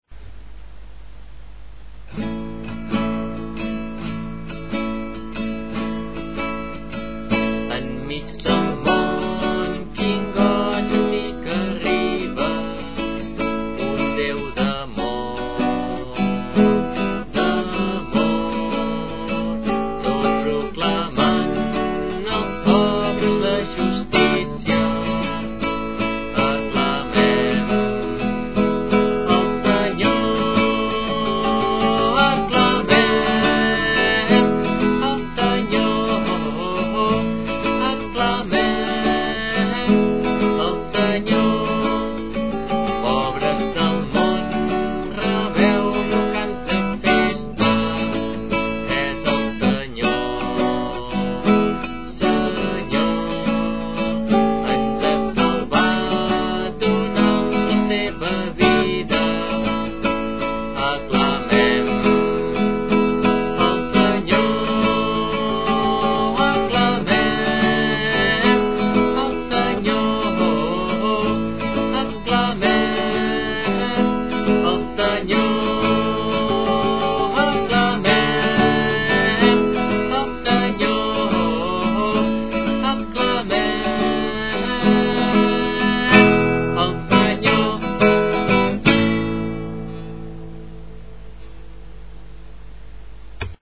a la flauta travessera
guitarra i veu.
i formar el grup de guitarra i flauta del Convent d’Arenys.